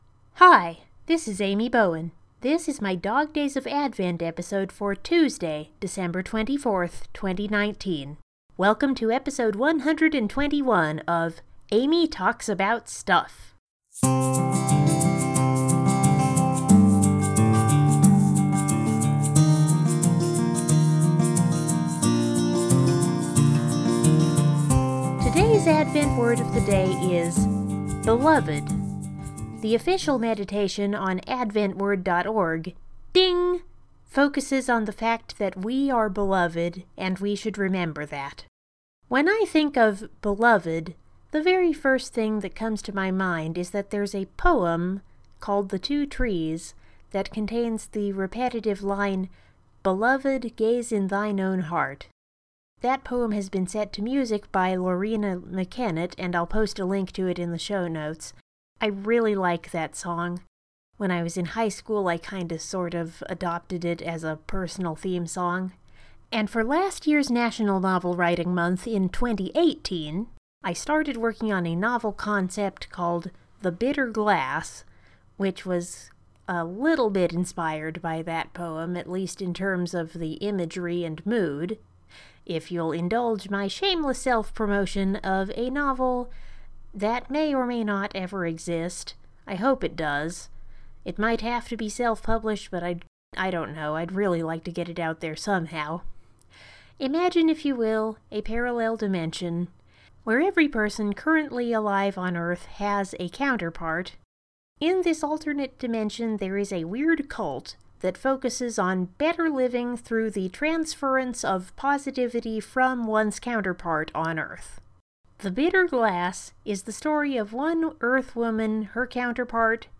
More of a reflection today, plus another silly song.